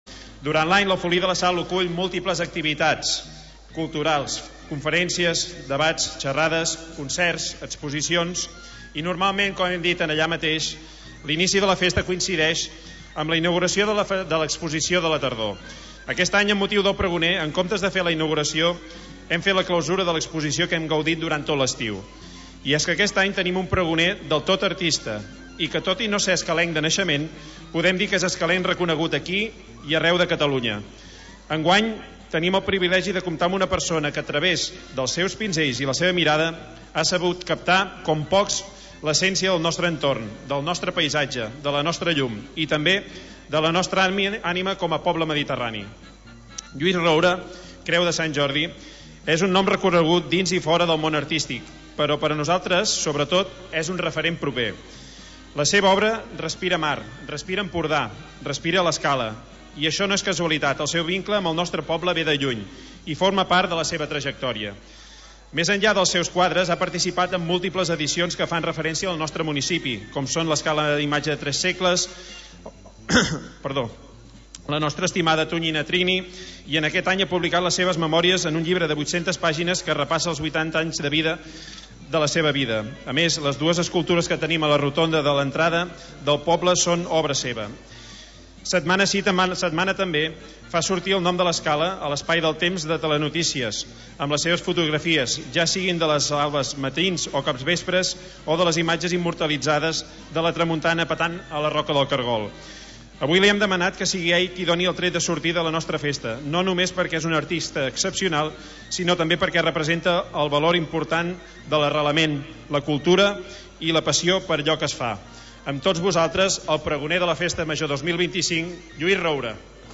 Pregó
que ha donat el tret de sortida oficial a cinc dies de Festa Major de l'Escala.